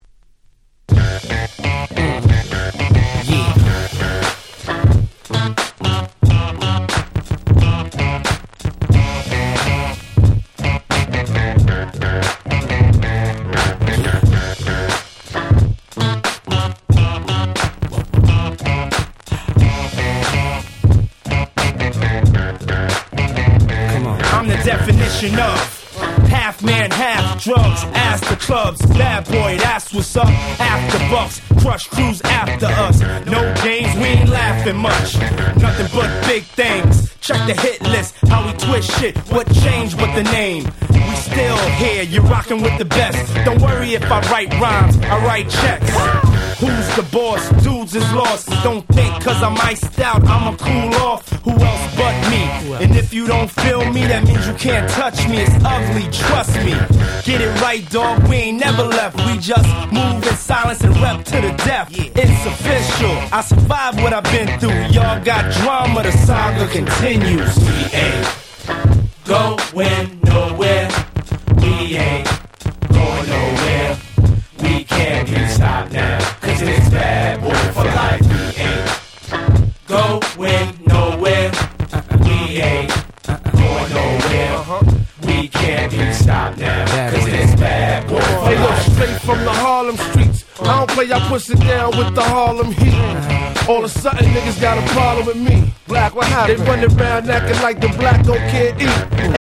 【Media】Vinyl 12'' Single
超絶Club Hit 00's Hip Hop !!!!!